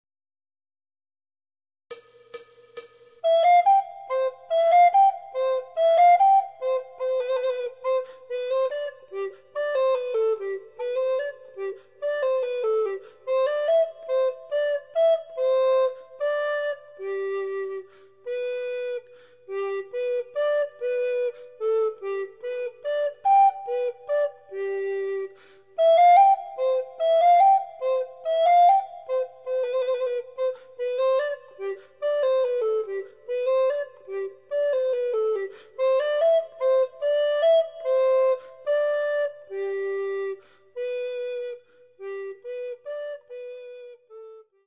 Recorder